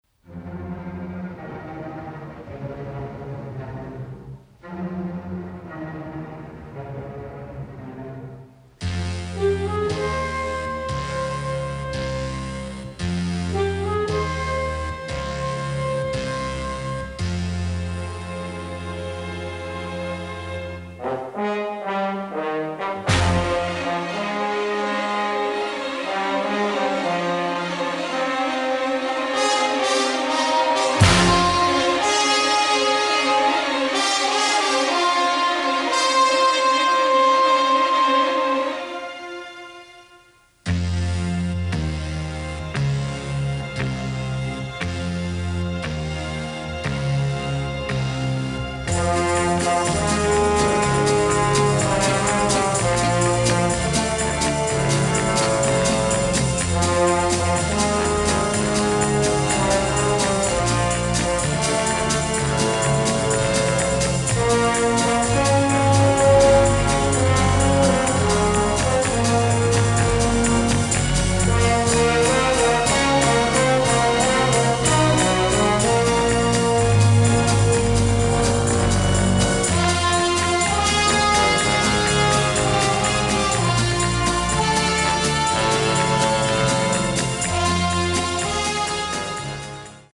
(Unused Film Version)